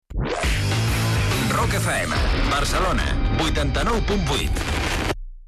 9d1a04e6f6ab7a7b85bede27c69cd4d81e1d1c64.mp3 Títol Rock FM Barcelona Emissora Rock FM Barcelona Cadena Rock FM Titularitat Privada estatal Descripció Identificació de l'emissora a Barcelona i freqüència.